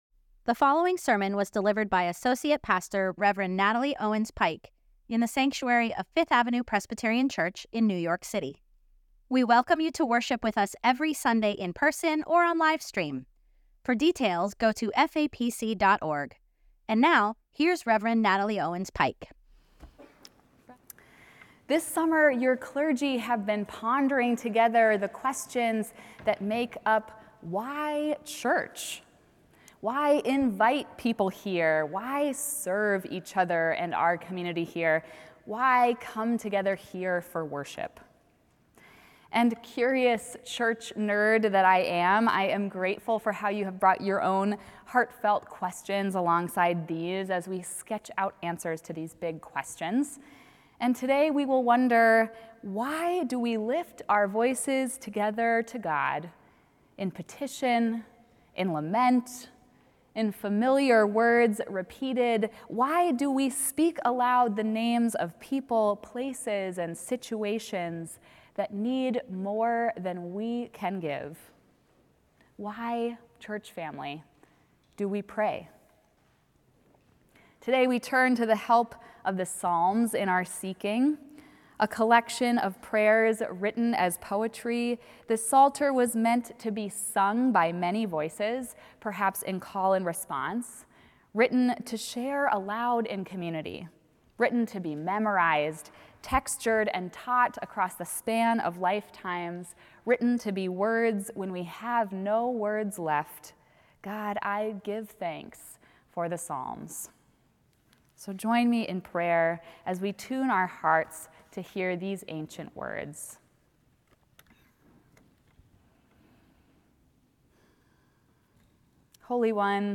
Sermon: “Why Pray?” Scripture: Psalm 146 Download sermon audio Order of Worship P raise the Lord!